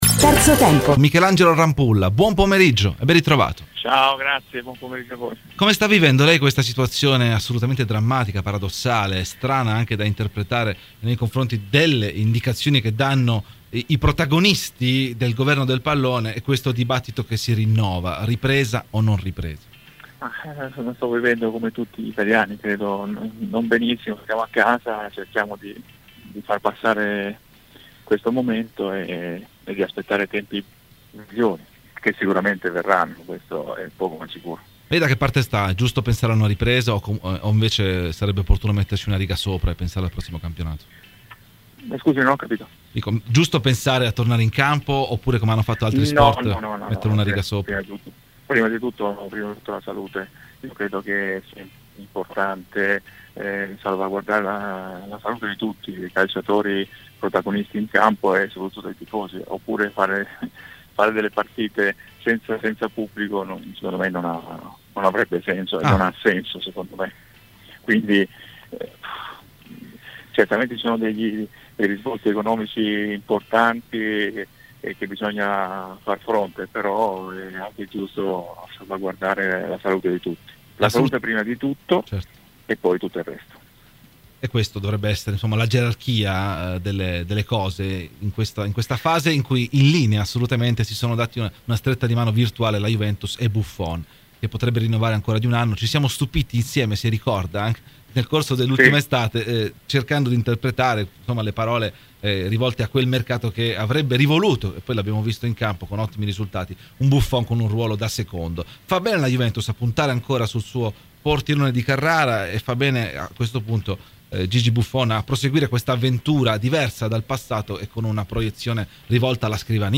Michelangelo Rampulla, ai microfoni di "Terzo Tempo".